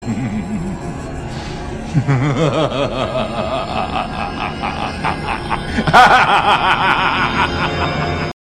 Злой смех - Звуковые мемы — смех, который звучит!